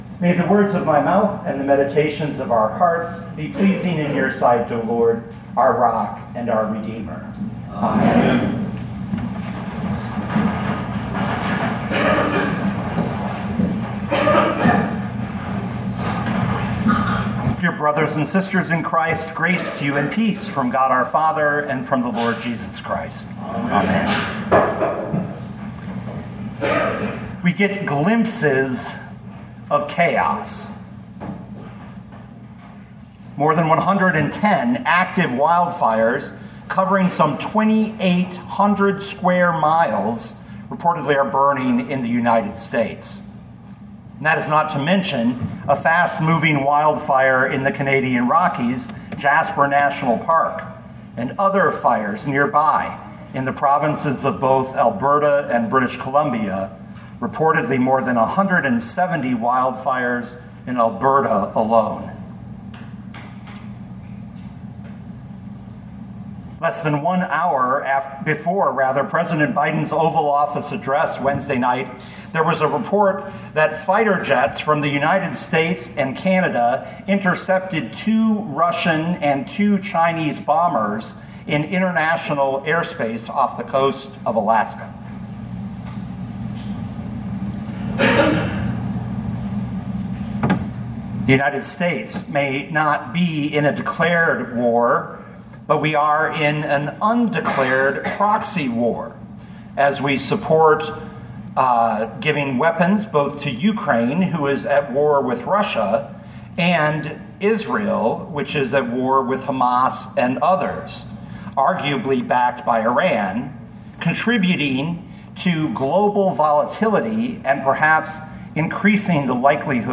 2024 Mark 6:45-56 Listen to the sermon with the player below, or, download the audio.